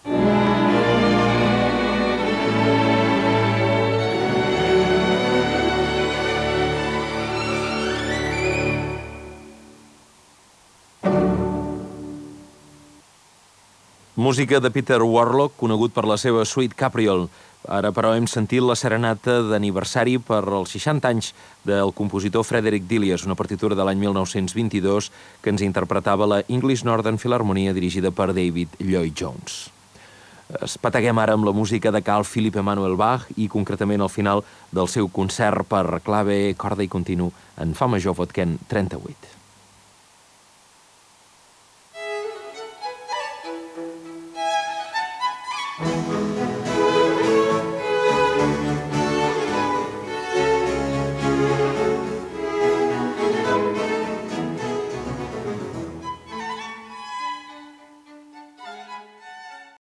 41b344f80a71a0c17923daa168476f23056b5001.mp3 Títol Catalunya Música Emissora Catalunya Música Cadena Catalunya Ràdio Titularitat Pública nacional Descripció Presentació d'una peça musical de Bach. Gènere radiofònic Musical